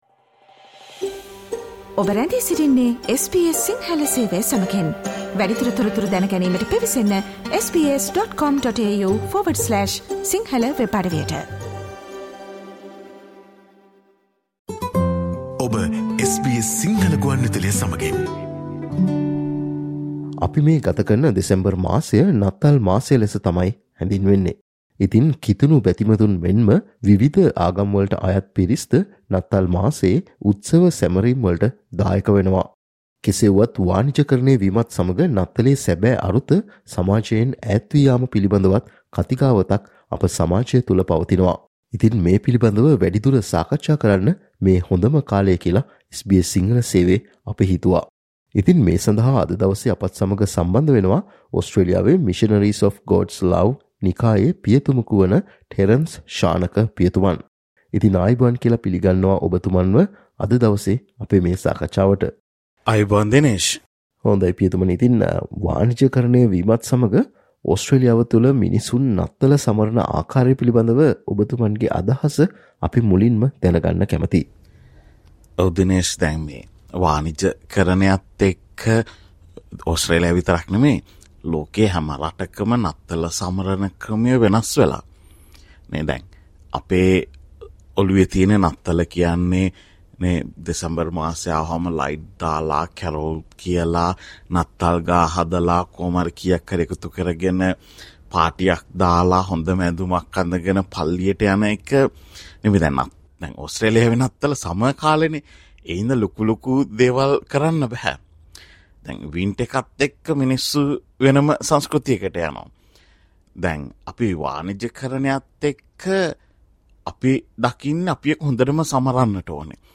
SBS Sinhala discussion